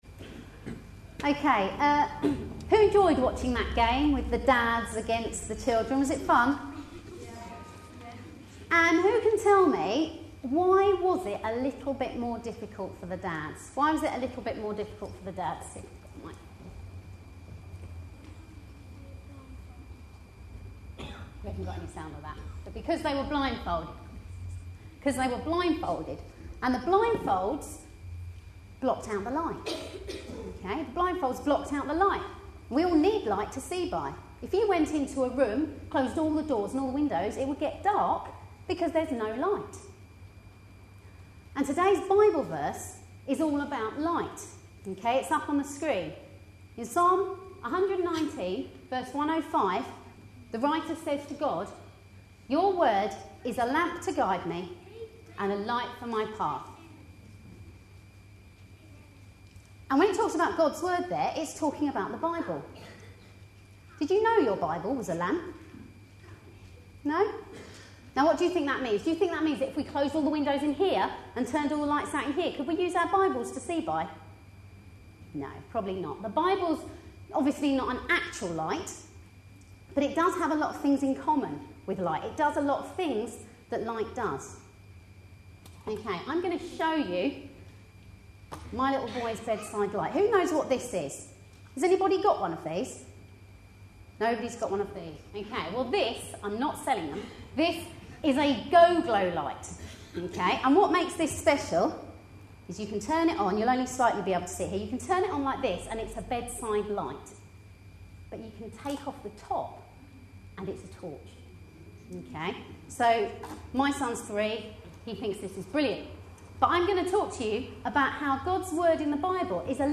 A sermon preached on 19th June, 2011.
This was part of an all-age service held on Father's Day; earlier there was a `Dads vs. children' contest involving passing footballs along lines of contestants, with the dads handicapped by being blindfolded.